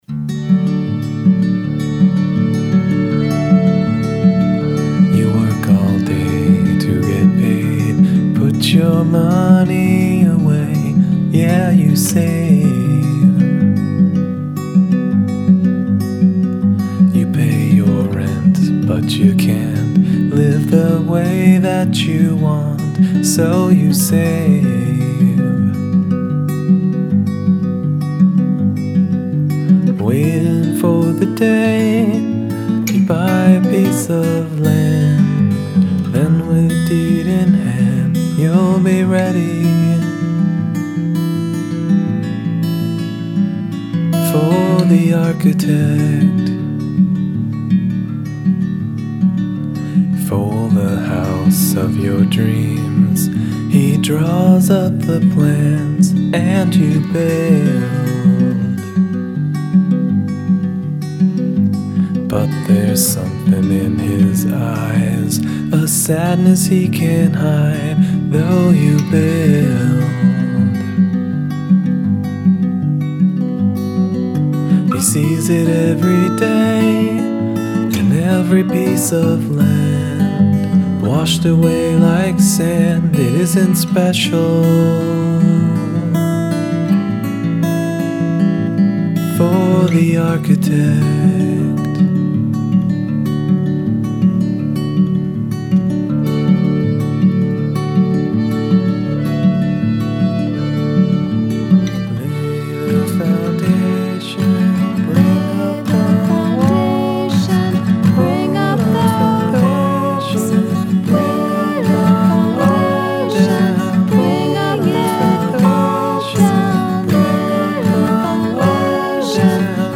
Must include a round.